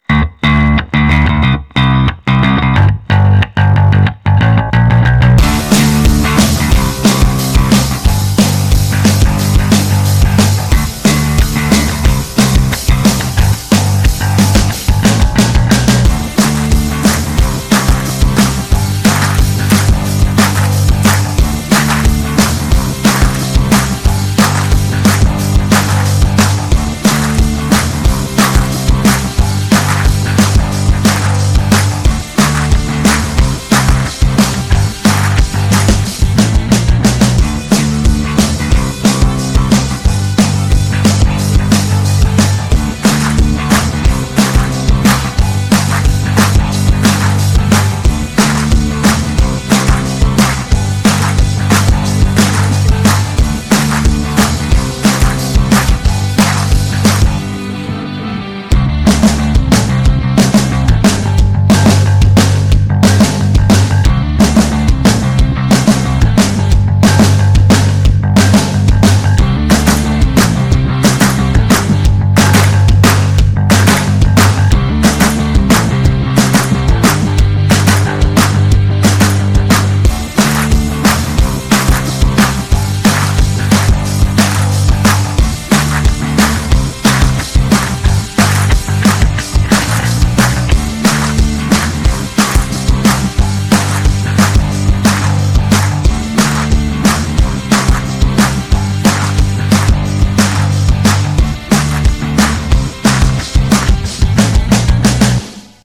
For the remix, I increased the volume of the drum and bass tracks.
Remix